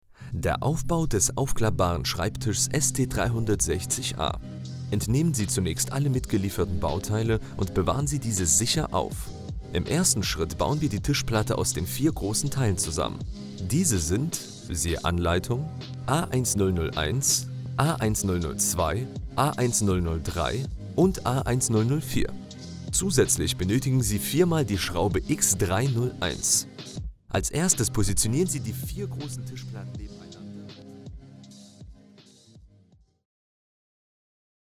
Professioneller, deutscher, männlicher Sprecher mit tiefer, angenehmer, autoritärer und entspannter Stimme.
Sprechprobe: Industrie (Muttersprache):
Professional German male voiceover with a deep, pleasant, authoritative and calming voice.
Germanvoice_Anleitung_2.mp3